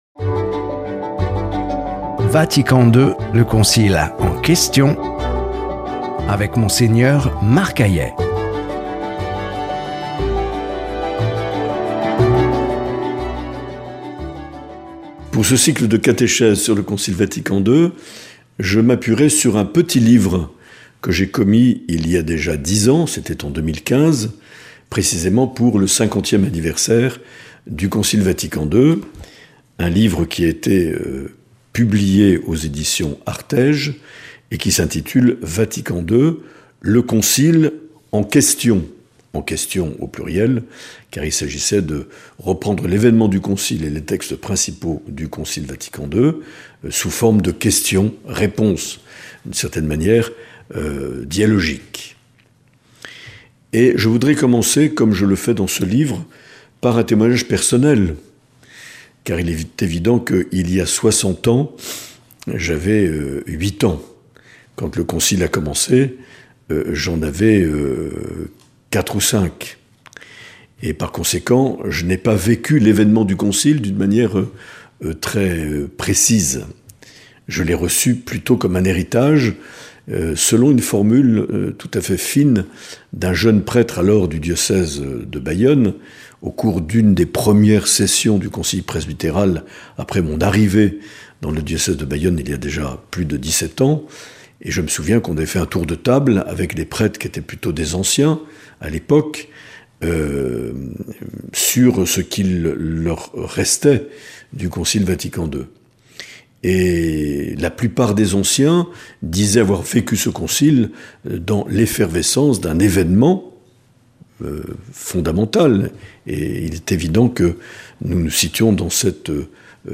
2 - Témoignage personnel
Monseigneur Marc Aillet
Présentateur(trice)